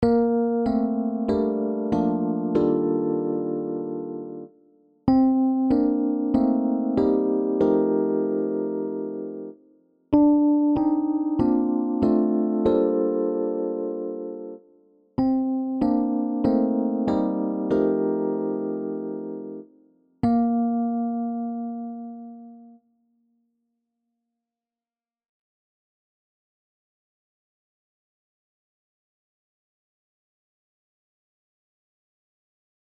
De vier zinnen van deze oefening beginnen eenstemmig en waaieren daarna uit naar zesstemmig. Elke partij heeft in dit geval een andere melodie (in tegenstelling tot veel van mijn andere inzingoefeningen).